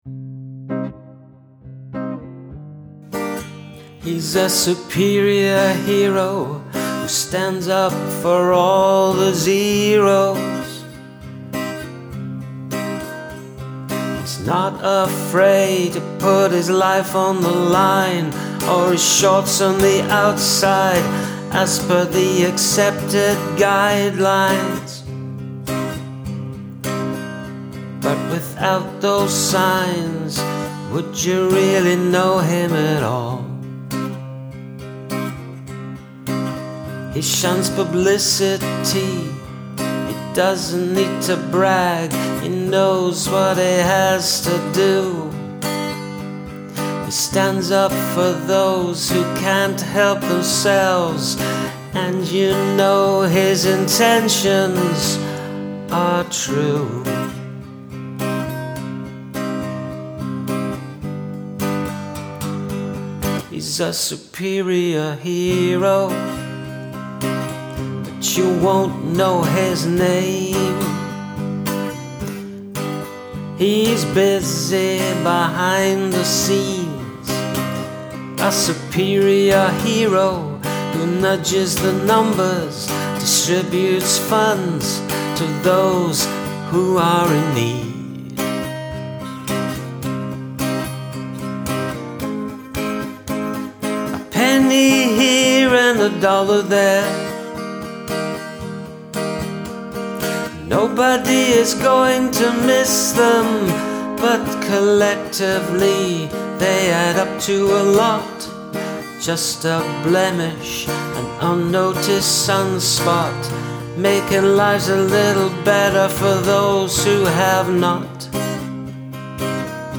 I dig the slower vibe (but still has a nice drive to it).